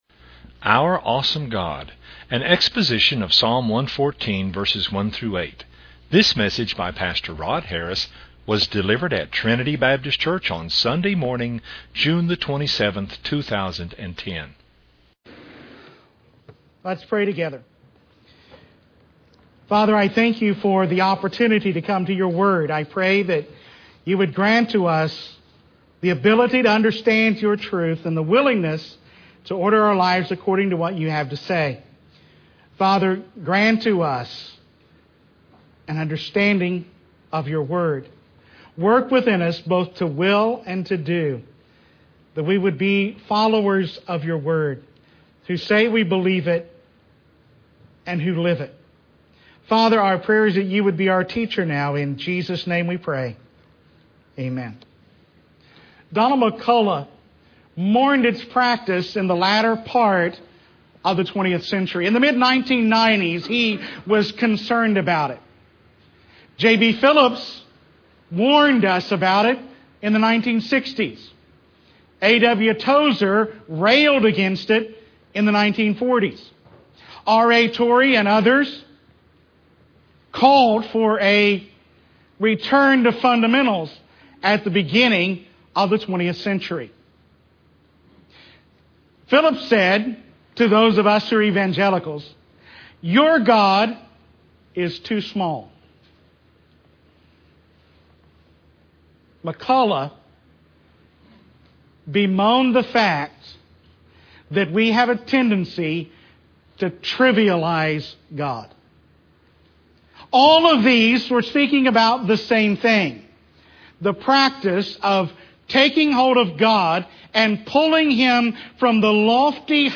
at Trinity Baptist Church on Sunday morning